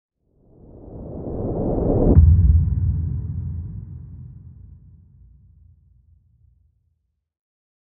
دانلود آهنگ تصادف 10 از افکت صوتی حمل و نقل
دانلود صدای تصادف 10 از ساعد نیوز با لینک مستقیم و کیفیت بالا